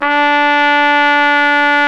Index of /90_sSampleCDs/Roland L-CDX-03 Disk 2/BRS_Trumpet 1-4/BRS_Tp 1 Class